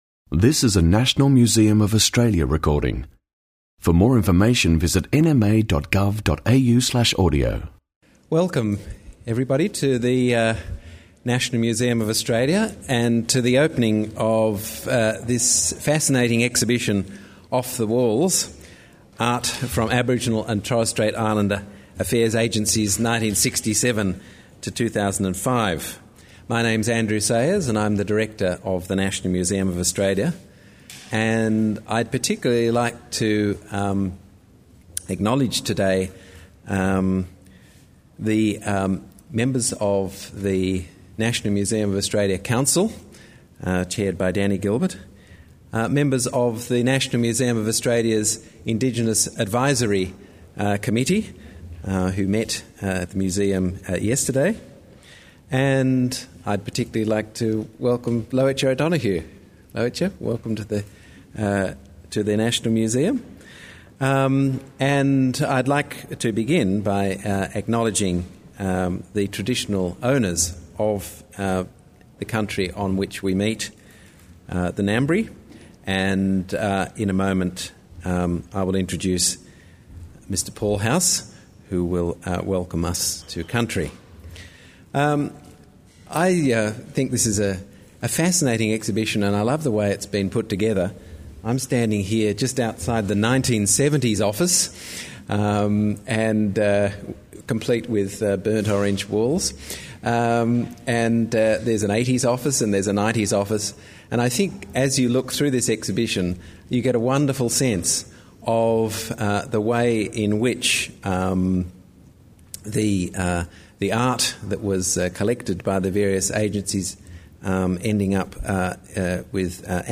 Off the walls: Art from Aboriginal and Torres Strait Islander Affairs Agencies 1967-2005: Exhibition launch | National Museum of Australia